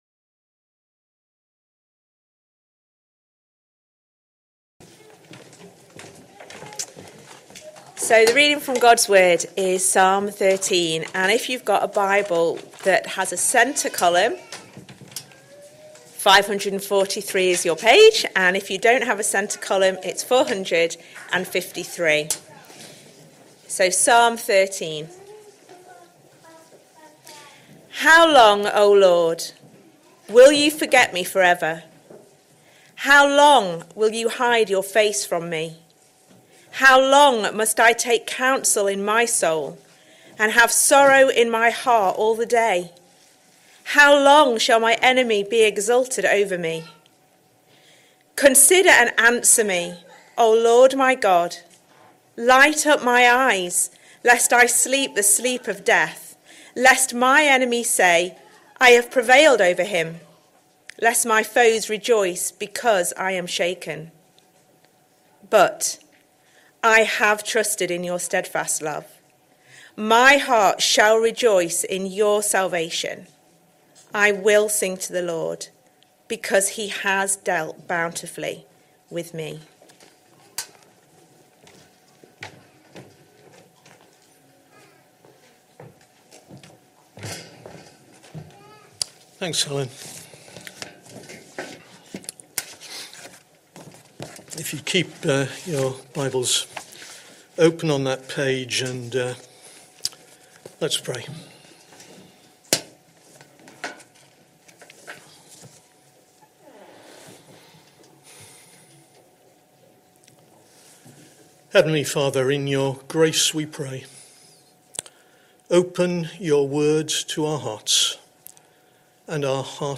Sunday Morning Service Sunday 15th June 2025 Speaker